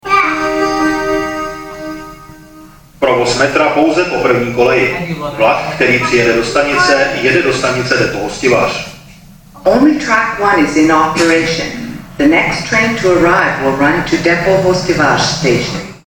- Staniční hlášení !Vlak jede směr Depo Hostivař" si